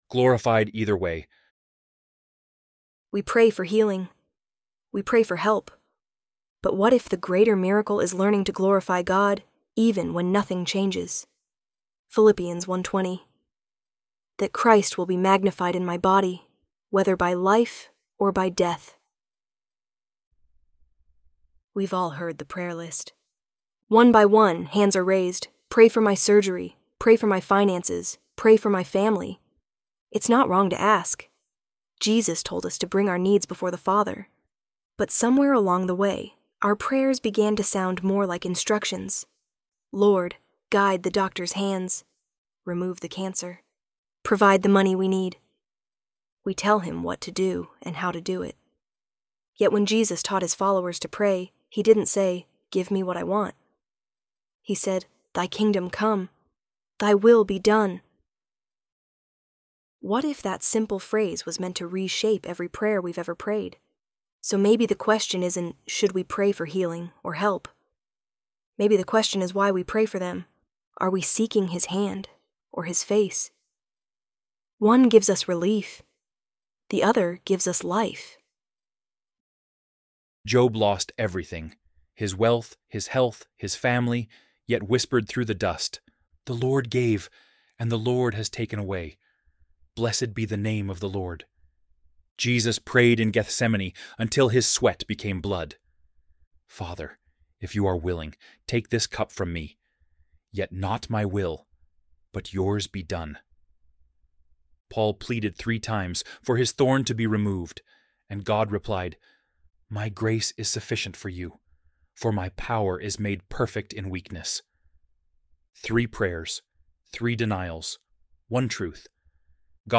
ElevenLabs_Glorified_Either_Way.mp3